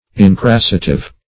\In*cras"sa*tive\